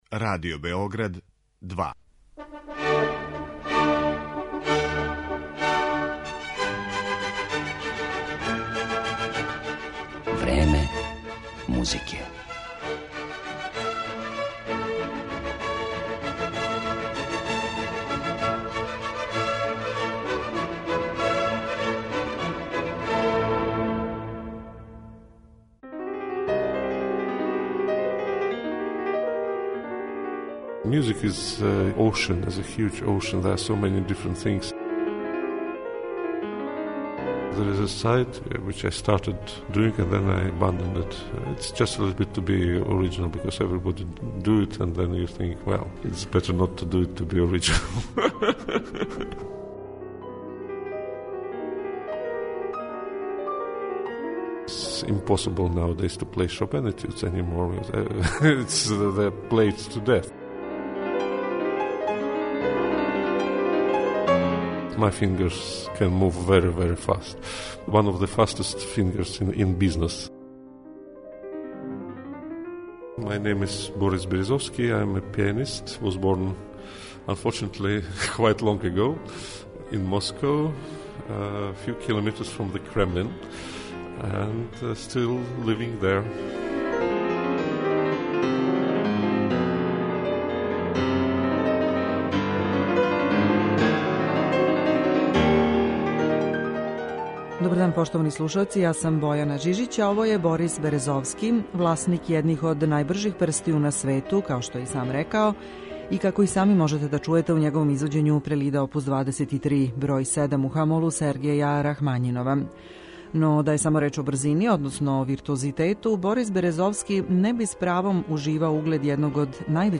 Славни руски пијаниста Борис Березовски, који је прошлог понедељка свирао у Београду, важи не само за једног од највећих клавирских виртуоза данашњице, него и за једног од најоригиналнијих извођача нашег доба. Данас ћемо га представити и кроз ексклузивни интервју снимљен са њим пред његов недавни београдски концерт.